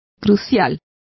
Complete with pronunciation of the translation of momentous.